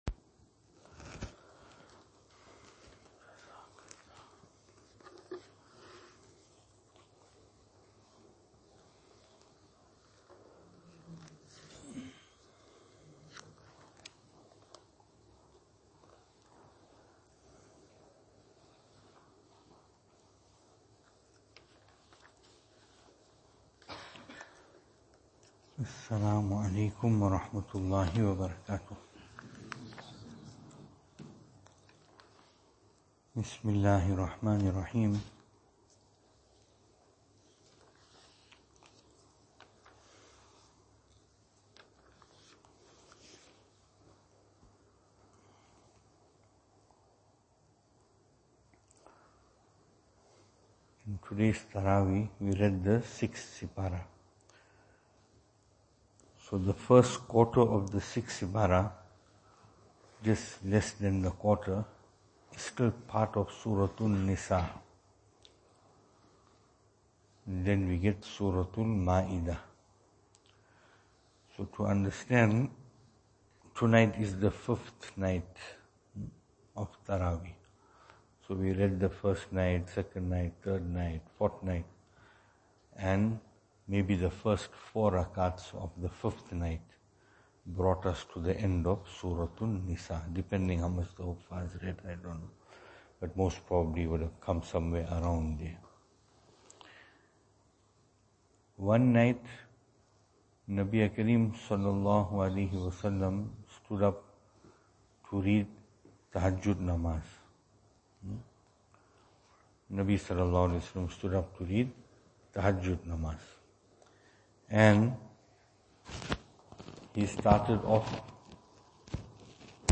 Venue: Albert Falls , Madressa Isha'atul Haq